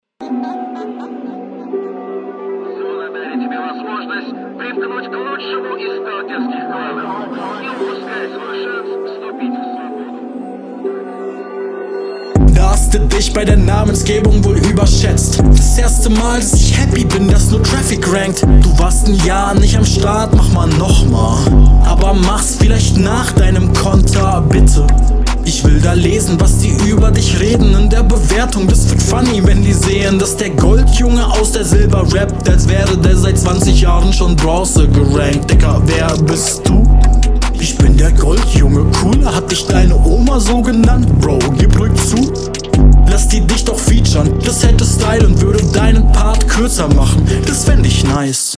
Nice Art, schön herablassend gerappt